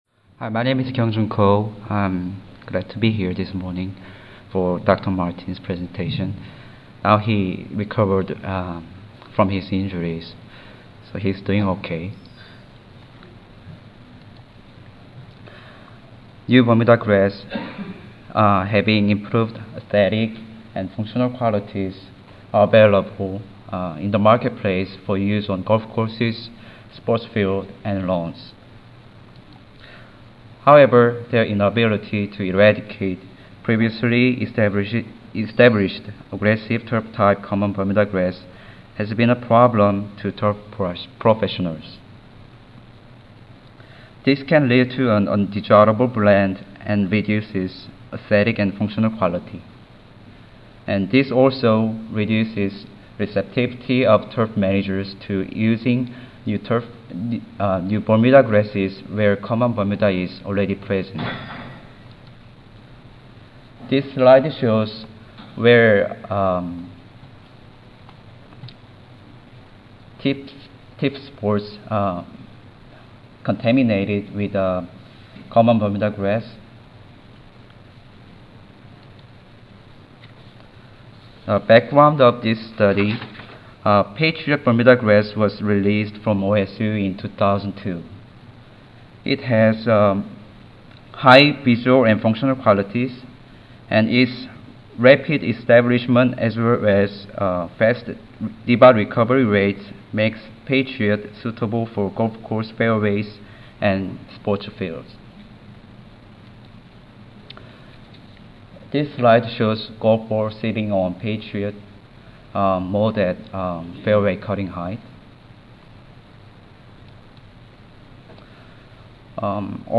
Evaluation of Competition Amongst Turf Bermudagrasses During Establishment Phase. Recorded presentation